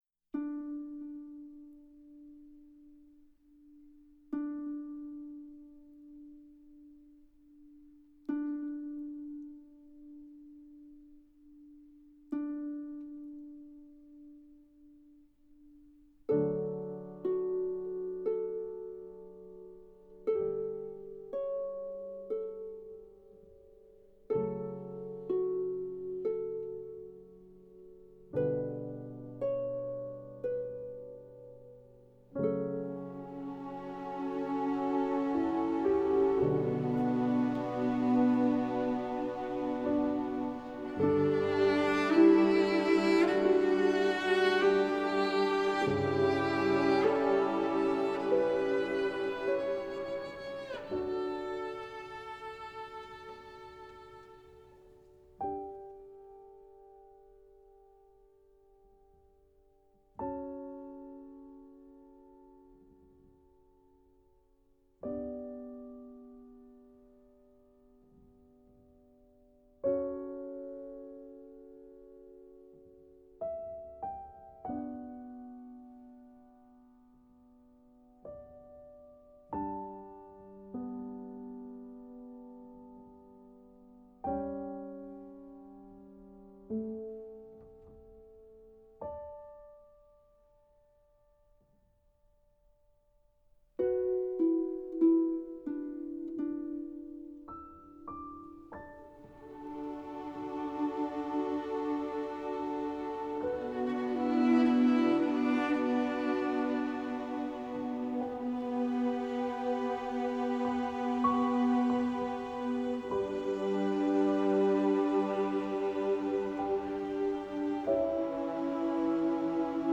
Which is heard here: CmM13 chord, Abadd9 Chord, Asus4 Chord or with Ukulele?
with Ukulele